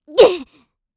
Hilda-Vox_Damage_kr_02.wav